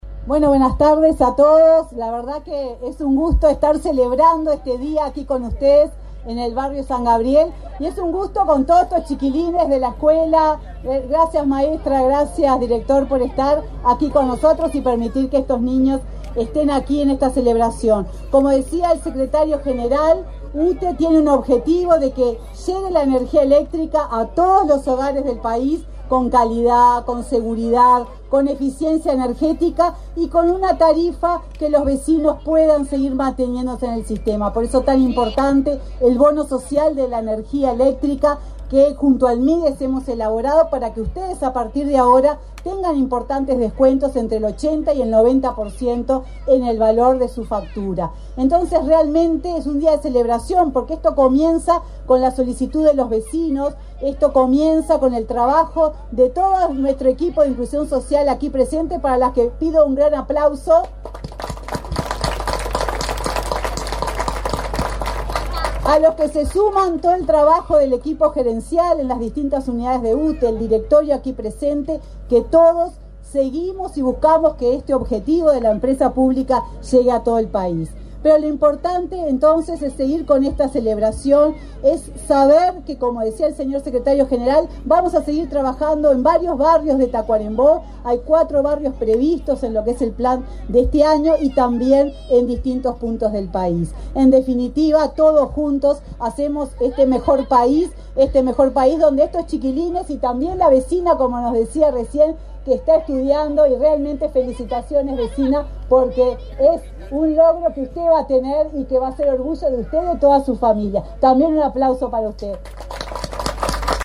Palabras de la presidenta de UTE, Silvia Emaldi
Palabras de la presidenta de UTE, Silvia Emaldi 15/08/2023 Compartir Facebook X Copiar enlace WhatsApp LinkedIn Autoridades de UTE inauguraron obras de electrificación en el barrio San Gabriel, en Tacuarembó, este 15 de marzo. La presidenta de la empresa estatal, Silvia Emaldi, realizó declaraciones en el evento.